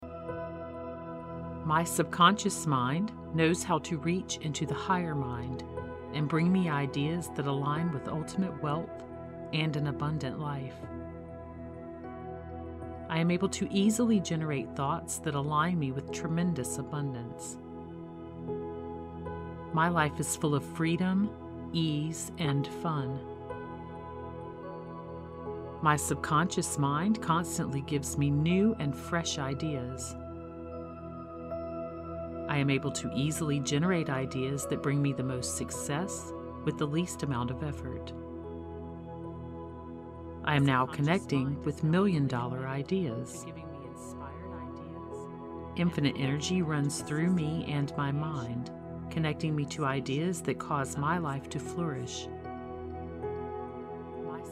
It contains both subliminal affirmations and non-subliminal affirmations that strongly infuse and “reprogram the subconscious mind” with your infinite and creative power. Each affirmation is delivered with dual-induction technology (switching from ear-to-ear and back to center) to impress your subconscious even further.
The sleep music is a theta binaural track. This meditation music has been tuned to 528Hz to assist you in programming your mind to manifest more quickly and promote a confident state.